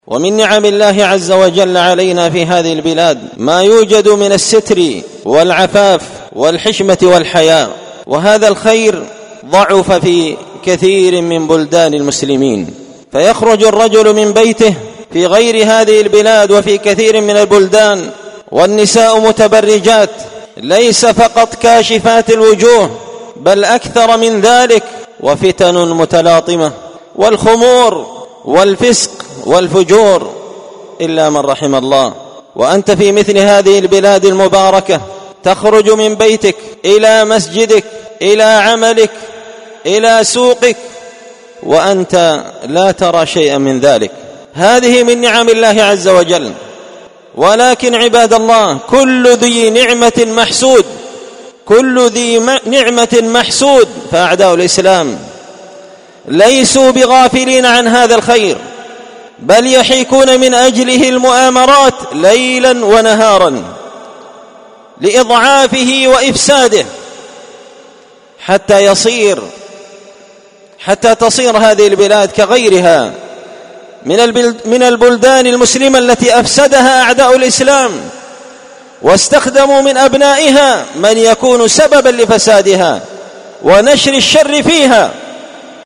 سلسلة مقتطفات من خطبة جمعة بعنوان حراسة الفضيلة وحماية المجتمع من الرذيلة ⏸المقتطف الثاني⏸اليمن والمتربصون والكائدون بها
دار الحديث بمسجد الفرقان ـ قشن ـ المهرة ـ اليمن